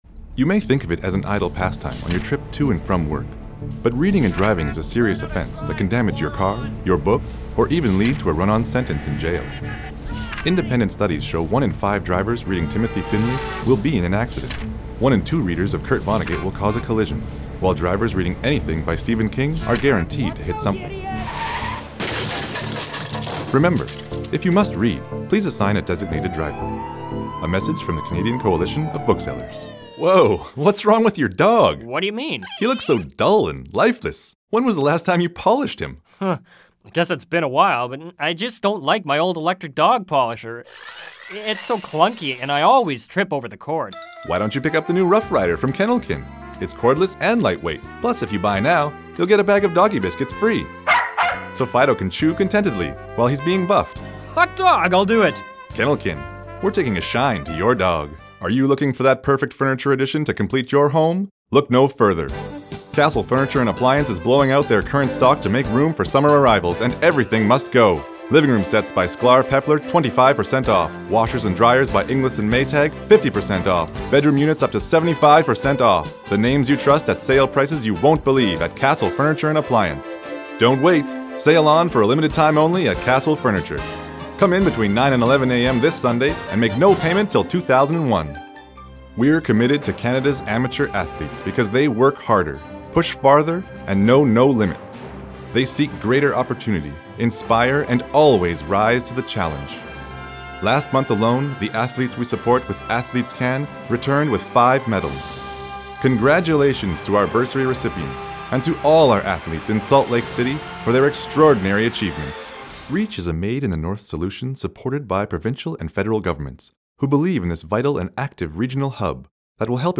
English Voice Demo